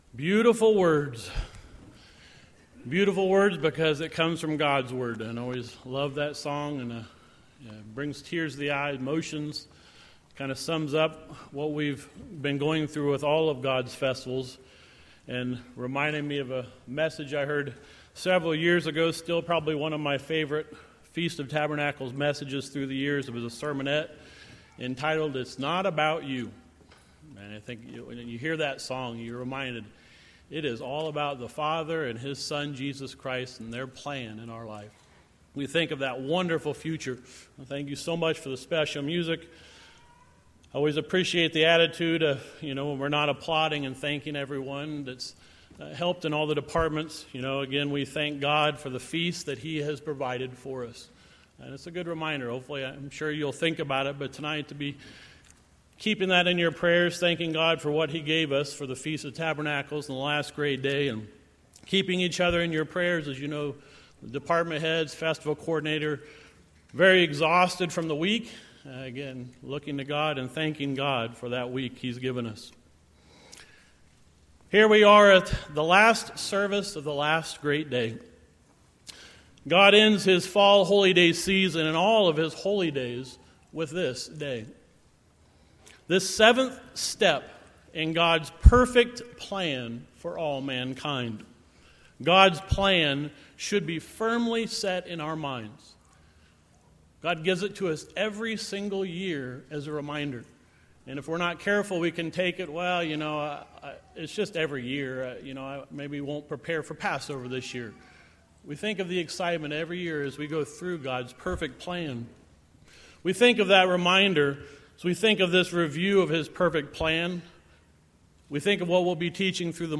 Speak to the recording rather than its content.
This sermon was given at the Snowshoe, West Virginia 2018 Feast site.